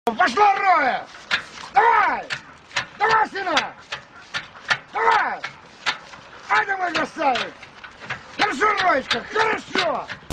A Dog Dragging A Car Sound Effects Free Download